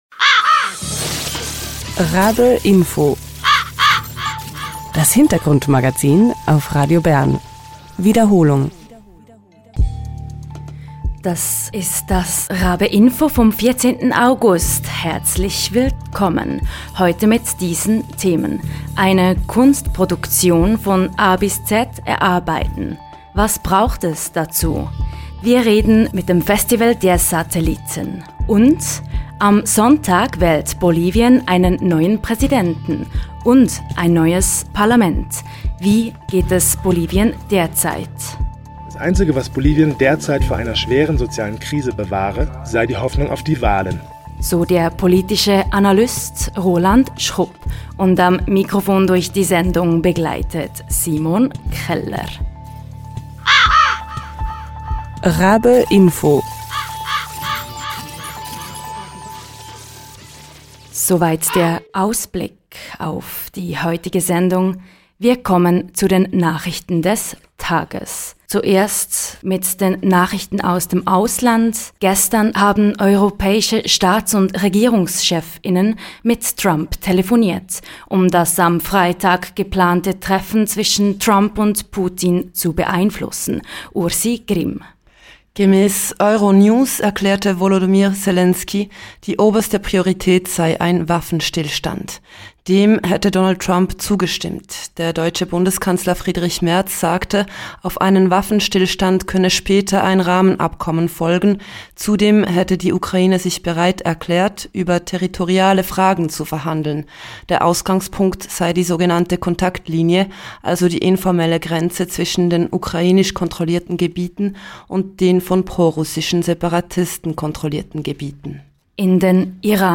Am Sonntag steht Bolivien vor einer wegweisenden Abstimmung: Die Wahlen zur Präsidentschaft und zum Parlament könnten eine politische Wende einleiten. Wir hören eine Analyse der Kolleg:innen von der Onda-Redaktion des Nachrichtenpool Lateinamerika.